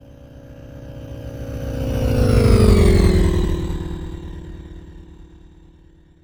Vehicle2_FlyBy_Left2Right_Long_200MPH.wav